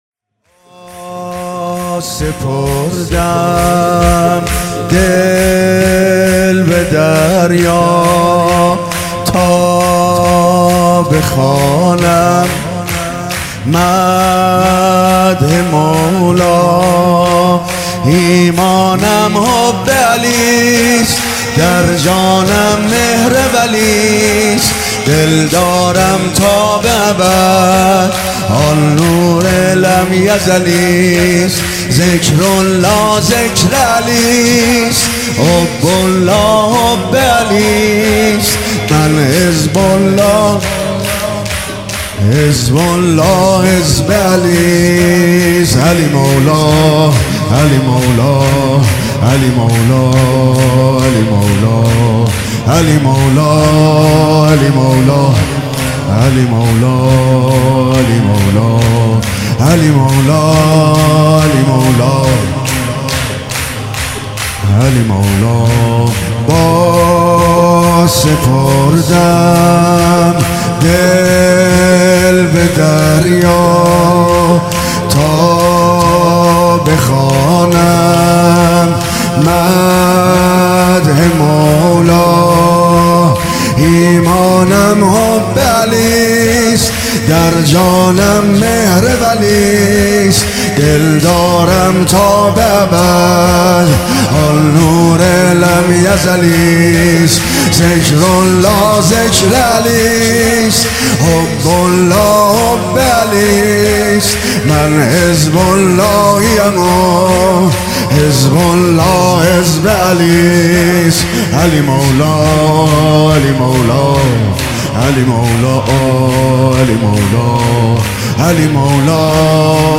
شب ولادت امام علی(ع)